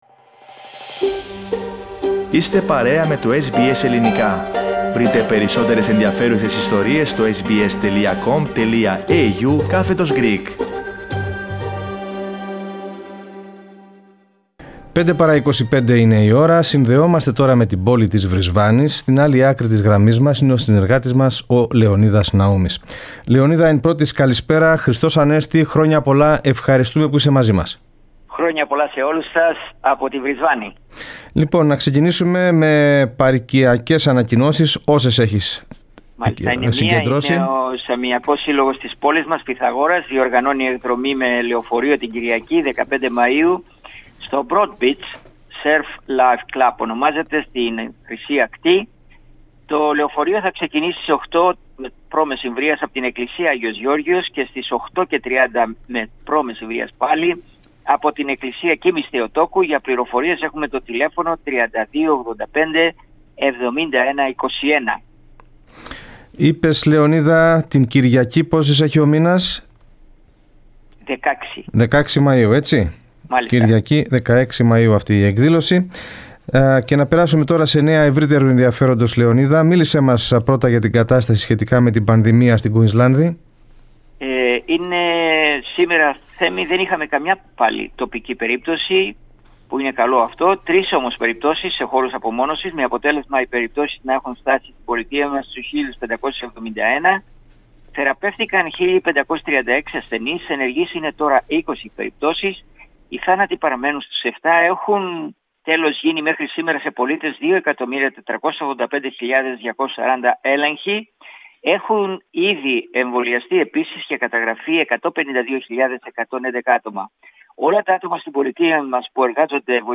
Kύμα εσωτερικής μετανάστευσης βιώνει η Κουηνσλάνδη αφού οι Αυστραλοί που επιλέγουν να εγκατασταθούν στην πολιτεία γνωστή ως Sunshine State και να απολαύσουν τις αχανείς παραλίες της αυξάνονται και πληθύνονται! Περισσότερα ακούστε στην ανταπόκριση